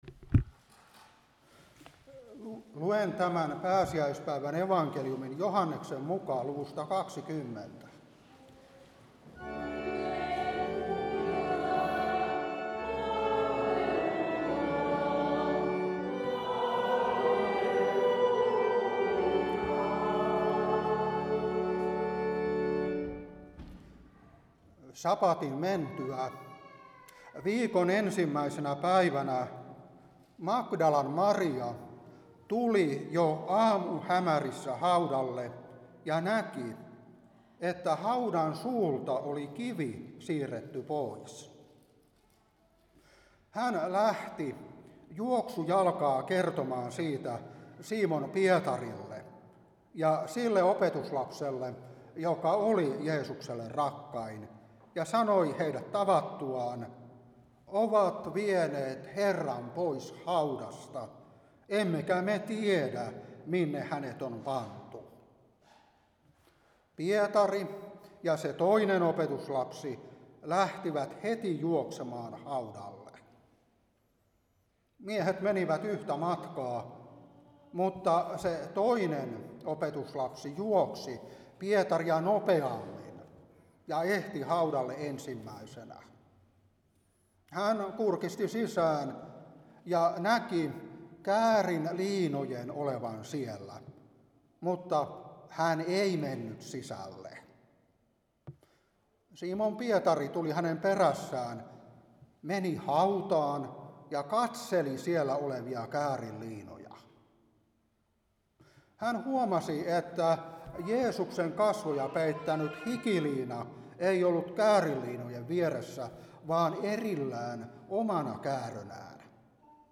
Saarna 2026-4 Luuk.20:1-10